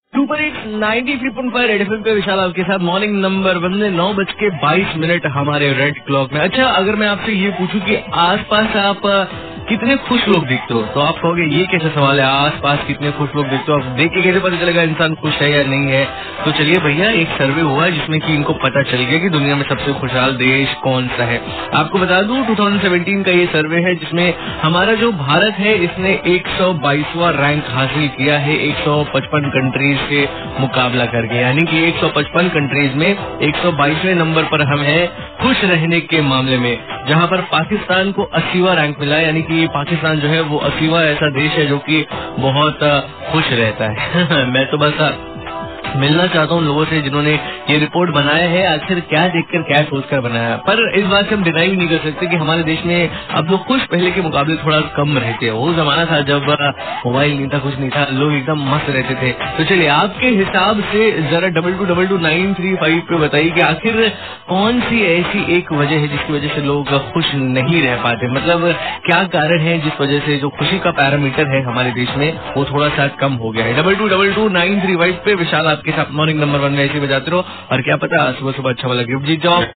RJ ASKING QUESTION FROM BANARASI LOG